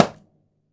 je_AntExplode
explode pop snap sound effect free sound royalty free Sound Effects